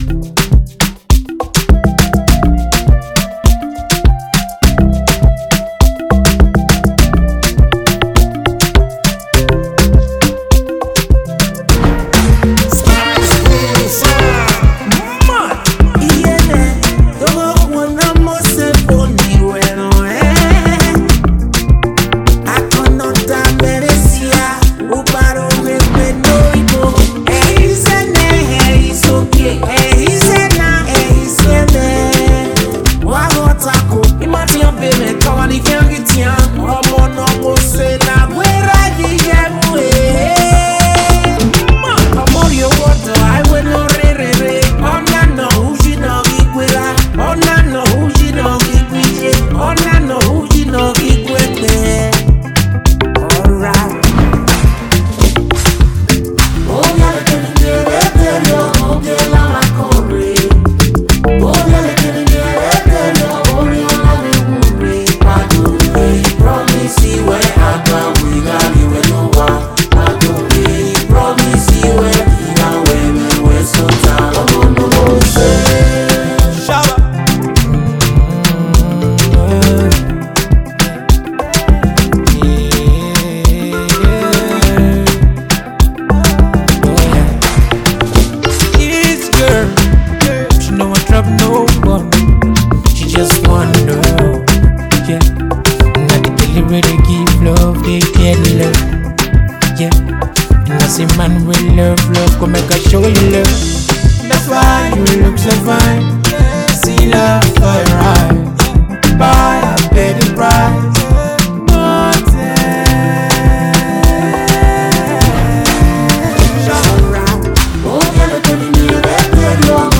Afrobeat style